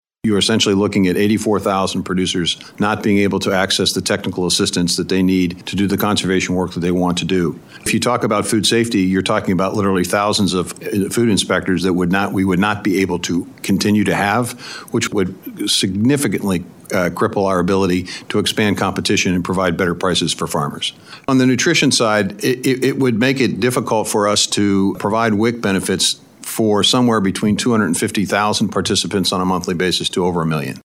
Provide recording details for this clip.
Partisan tensions boiled over at a recent House Ag Appropriations hearing as republicans battled USDA over the President’s proposed budget and regulations.